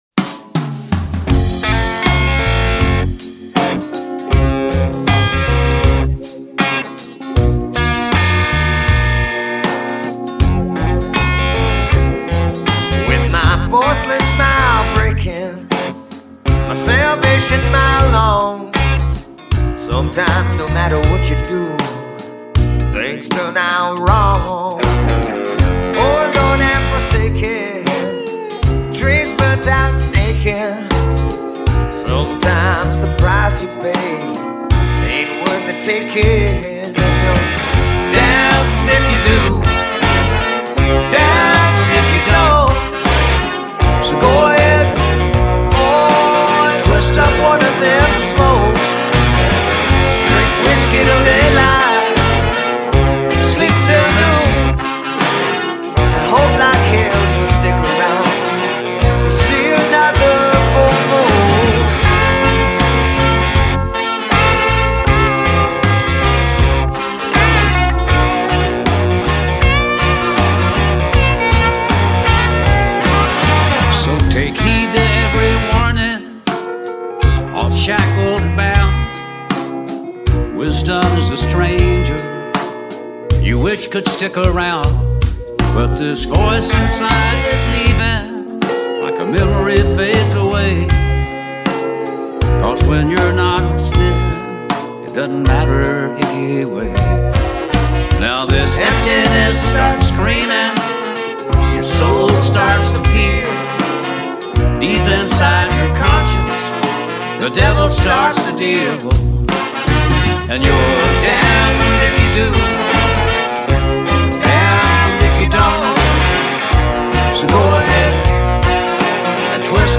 rootsy rock